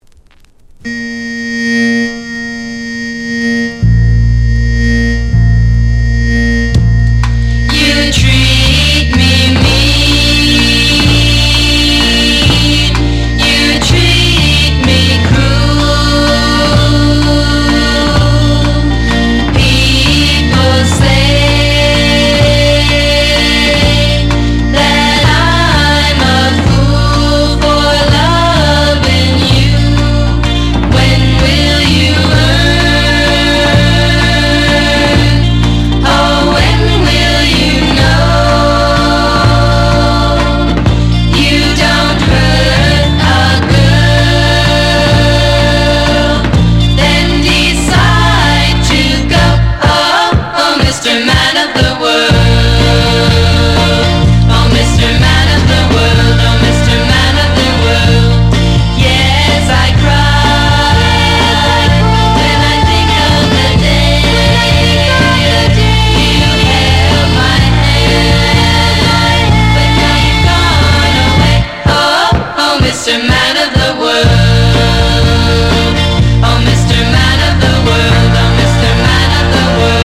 • 特記事項: MONO / DJ